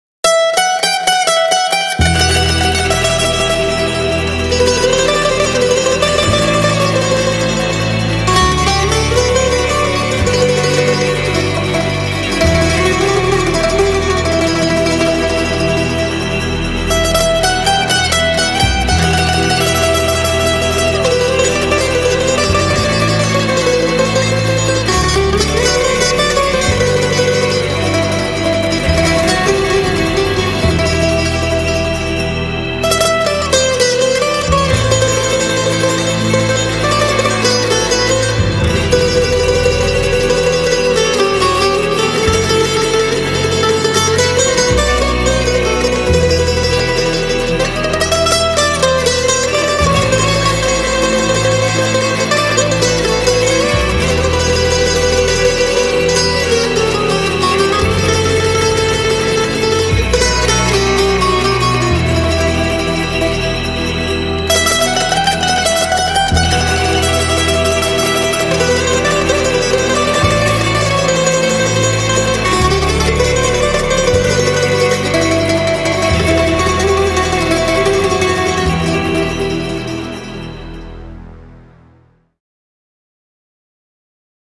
Enstrumental